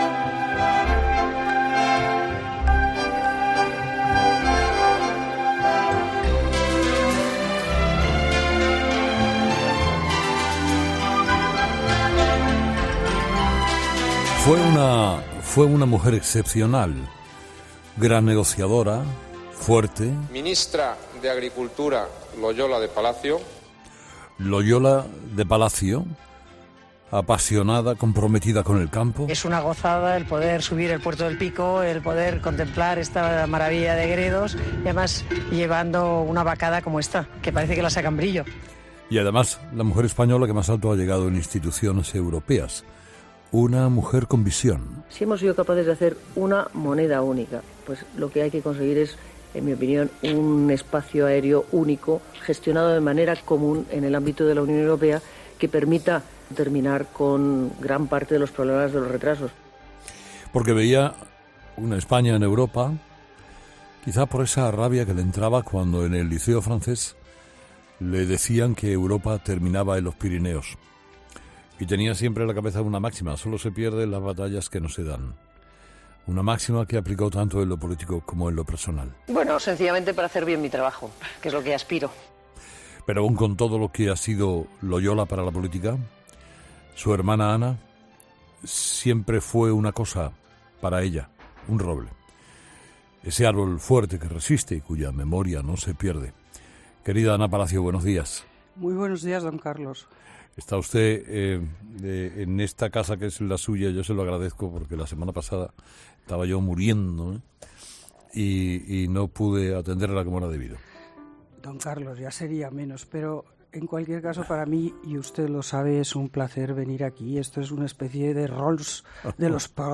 Al escuchar la voz de su hermana, Ana no puede evitar emocionarse en Herrera en COPE, porque la voz demuestra el paso del tiempo “ y no parece ella ”.